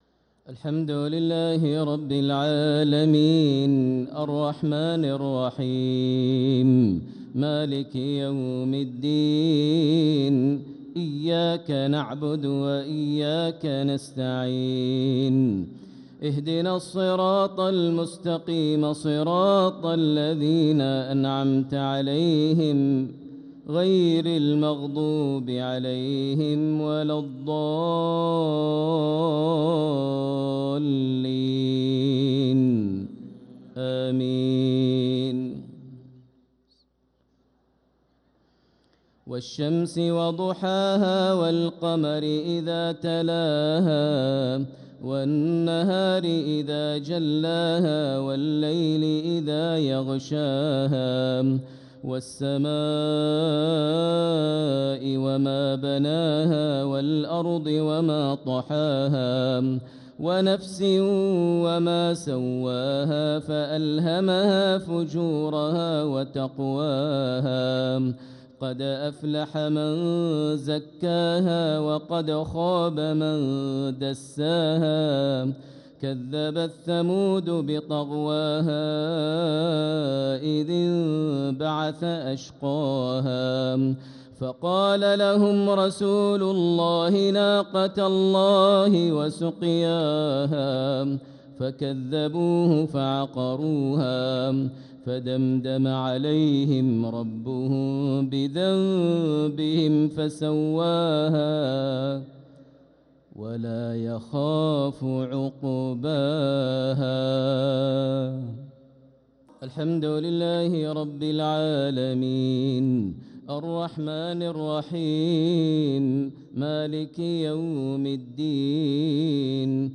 عشاء الأحد 2-9-1446هـ سورتي الشمس و النصر كاملة | Isha prayer Surat ash-Shams & an-Nasr 2-3-2025 > 1446 🕋 > الفروض - تلاوات الحرمين